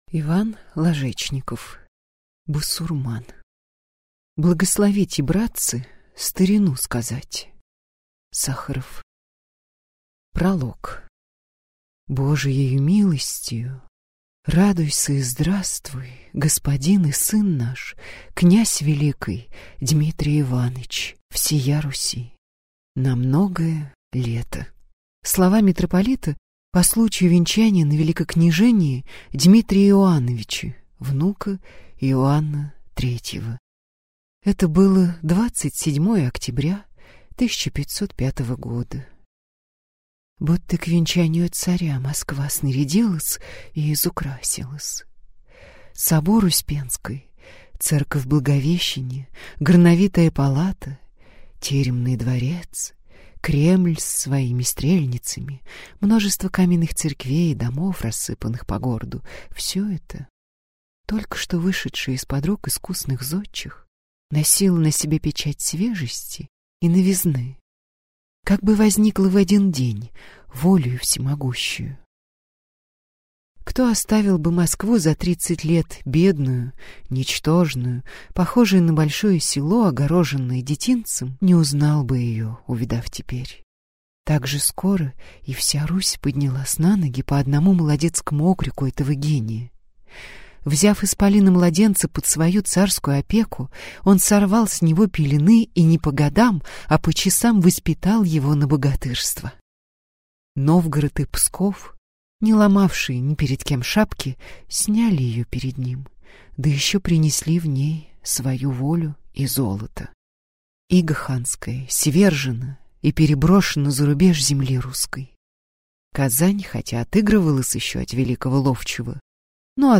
Аудиокнига Басурман | Библиотека аудиокниг
Прослушать и бесплатно скачать фрагмент аудиокниги